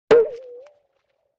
CARTOON - PLINK 02
Category: Sound FX   Right: Both Personal and Commercial